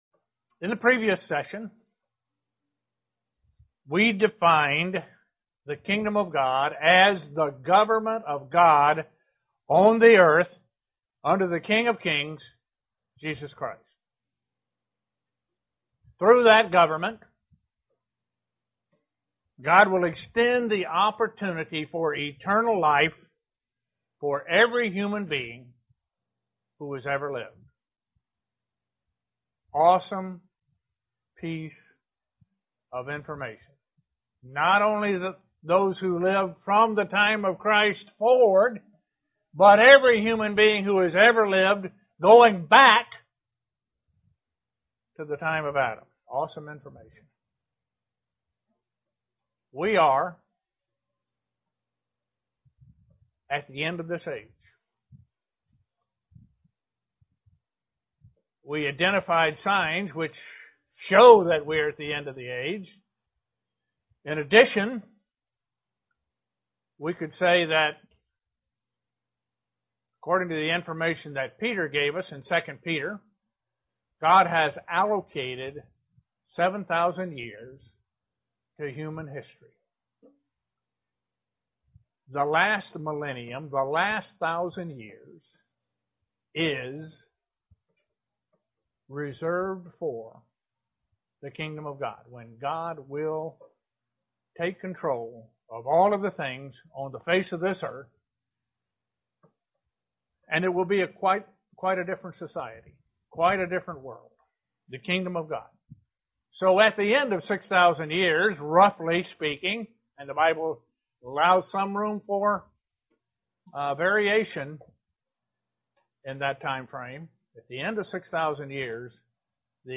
Kingdom of God lecture 2. Why the world need the Kingdom of God.
Given in Elmira, NY
To explain how we can be part of God's Kingdom right now even as we wait for the Kingdom to come in its fullnes UCG Sermon Studying the bible?